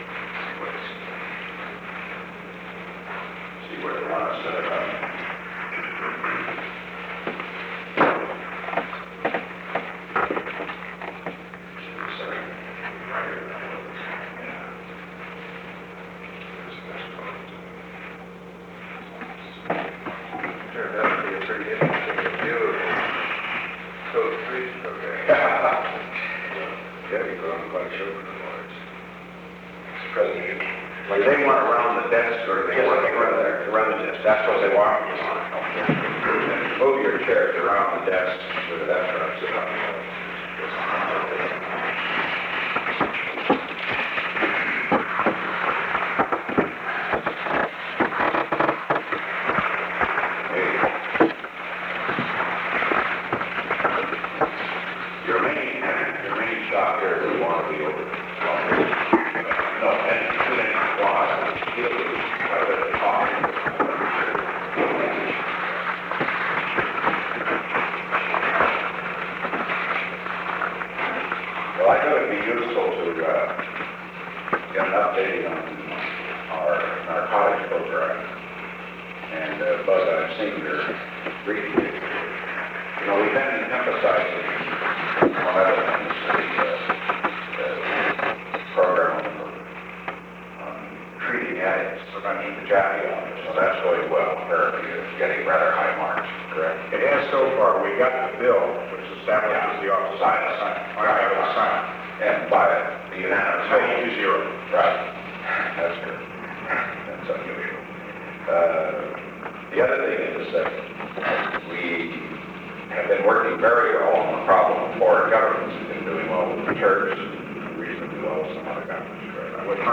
The Oval Office taping system captured this recording, which is known as Conversation 630-016 of the White House Tapes.